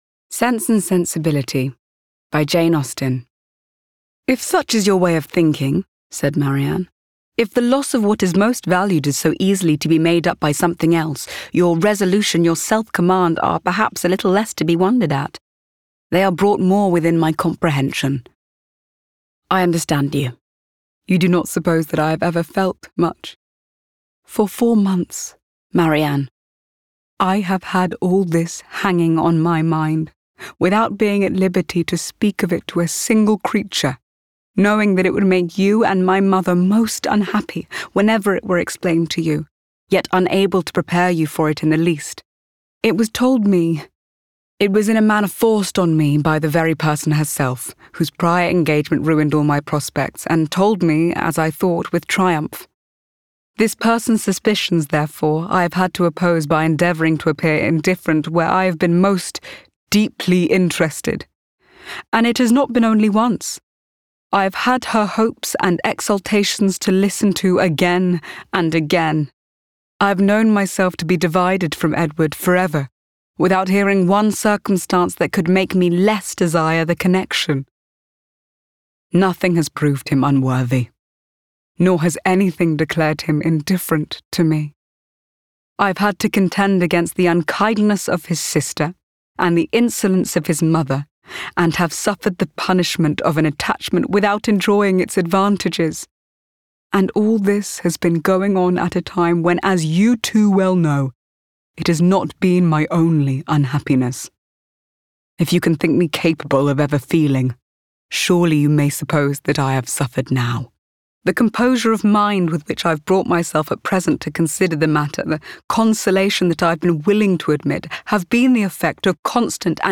RP
Female
Husky
Warm
SENSE AND SENSIBILITY AUDIOBOOK